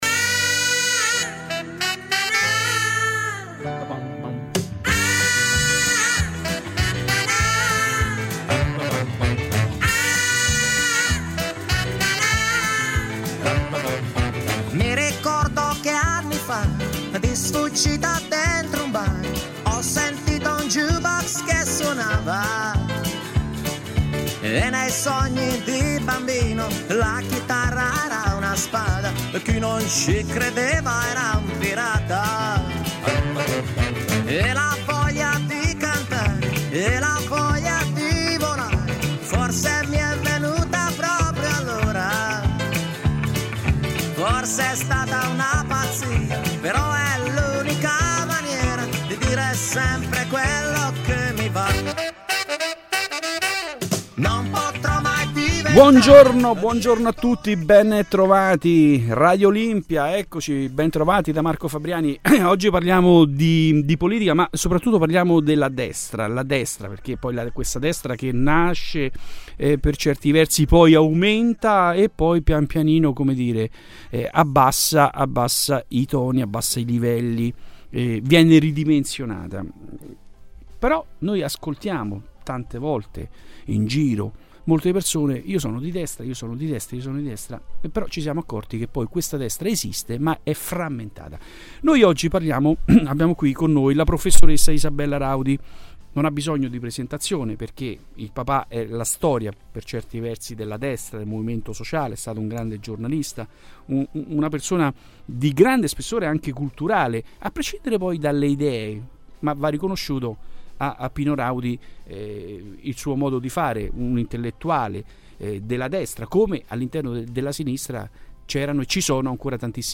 Trasmissioni radio e TV